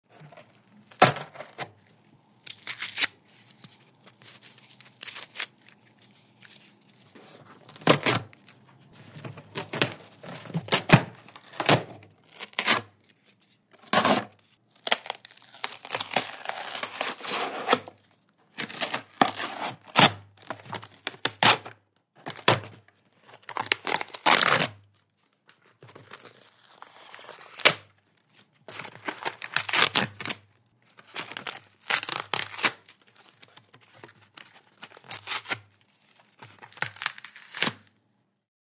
Звуки бумаги
Звук разрыва картонной упаковки вручную